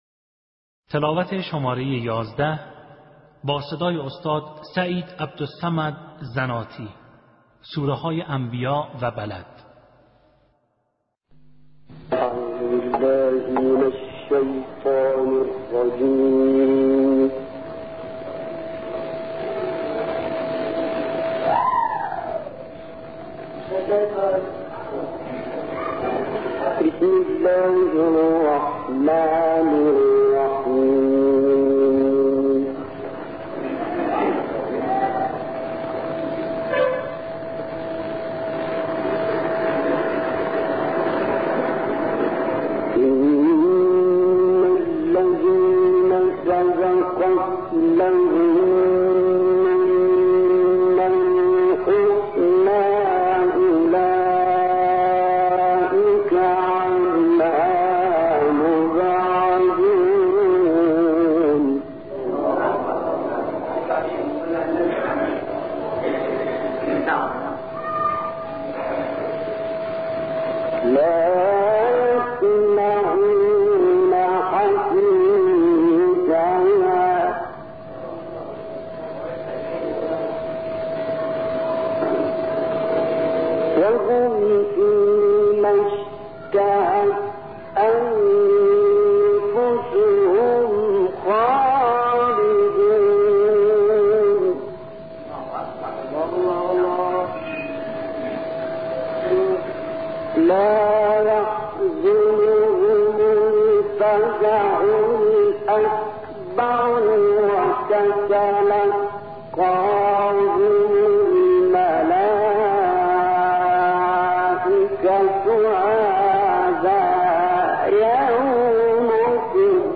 মিশরের বিখ্যাত ক্বারী "সায়িদ আল-জানাতী'র সুললিত কণ্ঠে আম্বিয়া সুরার তিলাওয়াত
মিশরের বিখ্যাত ক্বারী
বার্তা সংস্থা ইকনা: সম্প্রতি কুরআনের চ্যানেলে মিশরের বিখ্যাত ক্বারী "সায়িদ আল- জানাতী"র সুললিত কণ্ঠে পবিত্র কুরআনের আম্বিয়া'র ১০১ থেকে ১১২ ও বালাদ সুরার ১ থেকে ১৮ নম্বর আয়াতের তিলাওয়াত প্রকাশ হয়েছে।